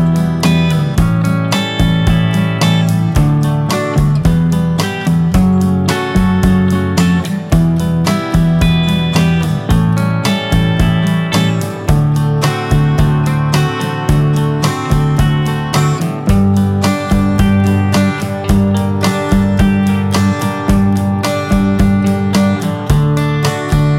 Minus Guitars Pop (1960s) 2:19 Buy £1.50